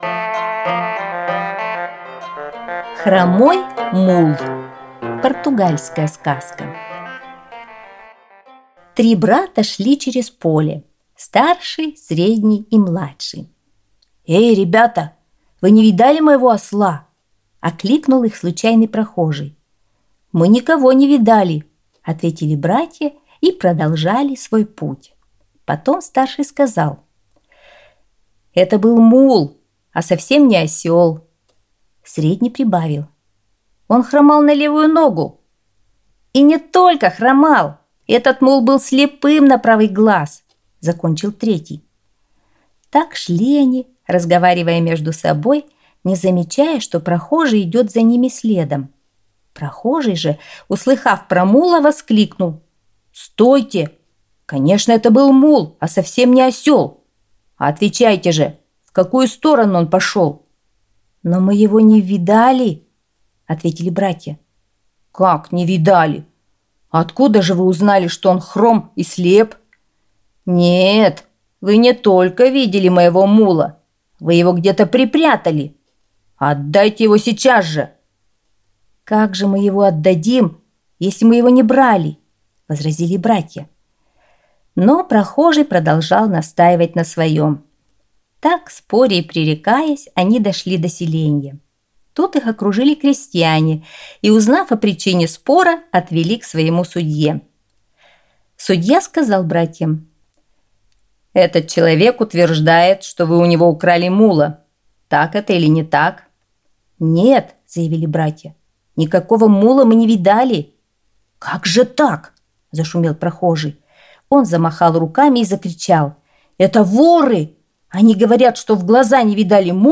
Аудиосказка «Хромой мул»